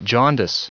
Prononciation du mot jaundice en anglais (fichier audio)
Prononciation du mot : jaundice